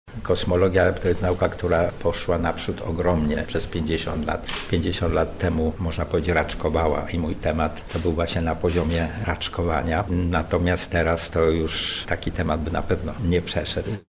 Kosmos i filozofia – tymi zagadnieniami od ponad 50 lat zajmuje się ksiądz prof. Michał Heller. Na Katolickim Uniwersytecie Lubelskim odbyło się uroczyste odnowienie jego doktoratu.
– mówi ksiądz prof. Michał Heller.